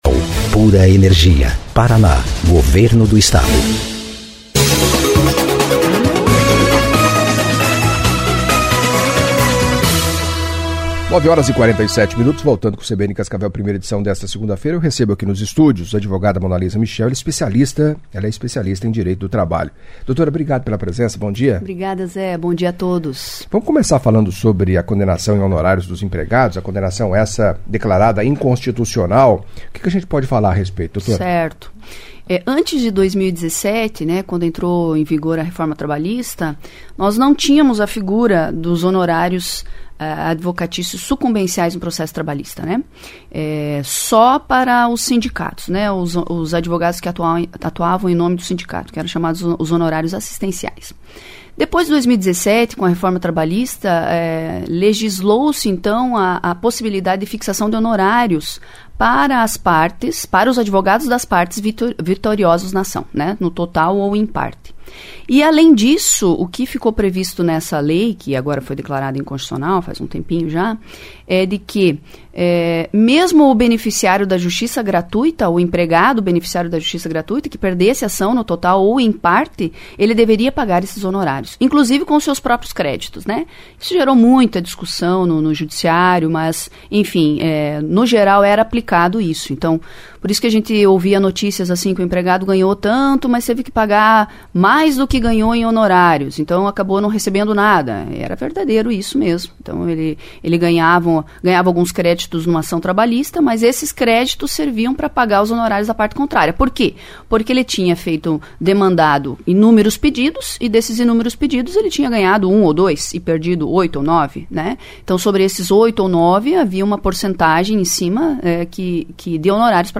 Em entrevista à CBN Cascavel
envolvendo empregador e empregado sem que haja incidência fiscal e ainda respondeu dúvidas de ouvintes